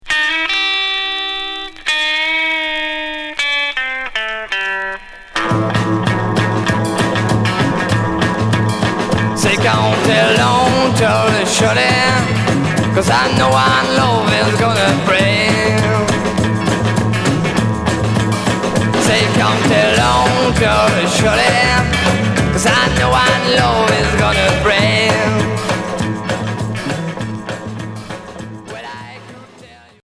Rock garage